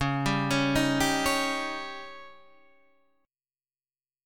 C#sus2b5 Chord
Listen to C#sus2b5 strummed